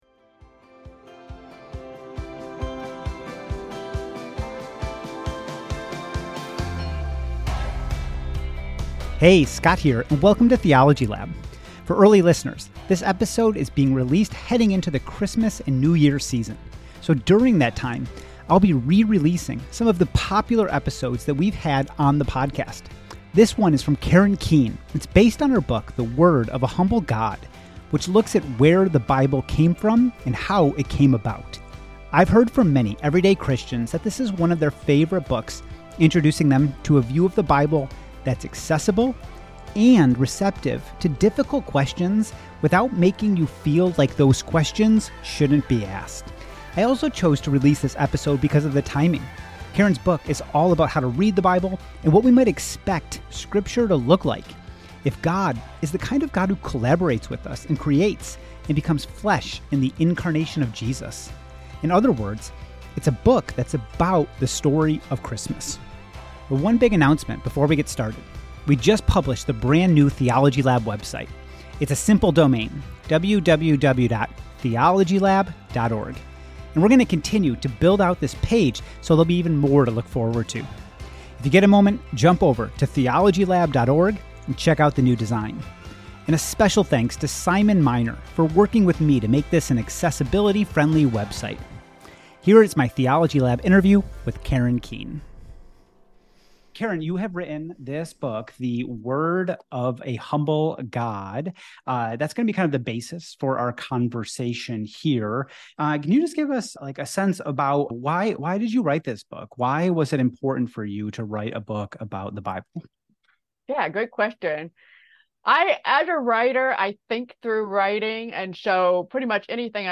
Episode Description This interview